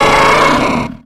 Cri de Tauros dans Pokémon X et Y.